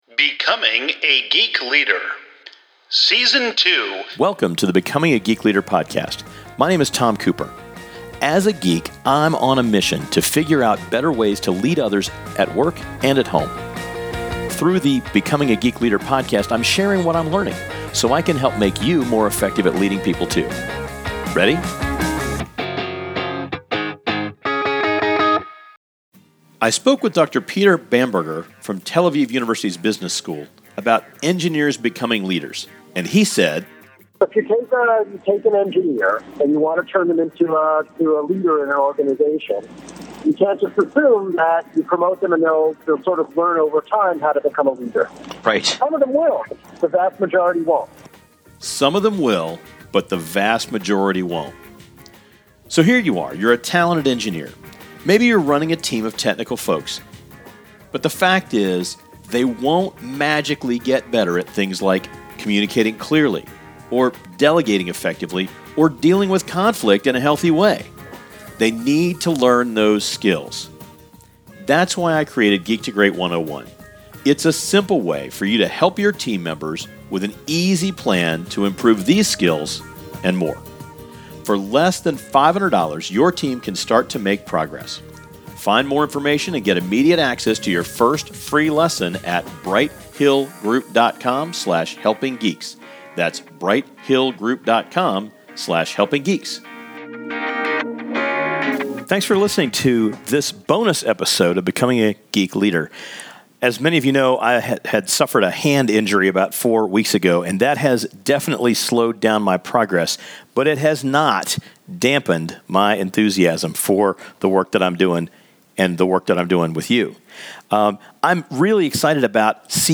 Thought Leader Interview